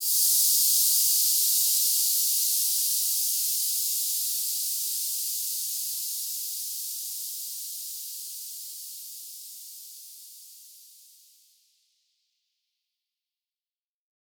Index of /musicradar/shimmer-and-sparkle-samples/Filtered Noise Hits
SaS_NoiseFilterC-01.wav